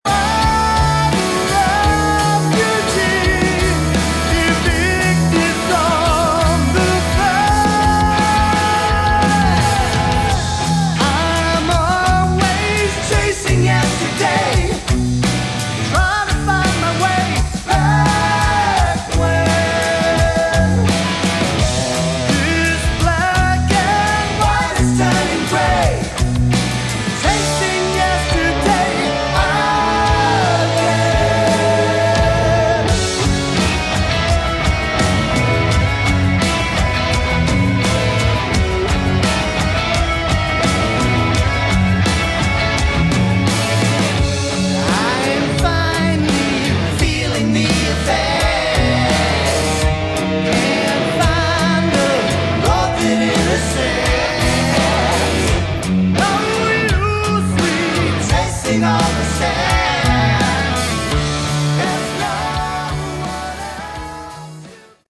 Category: Melodic Rock
vocals
guitar, bass, keyboards
drums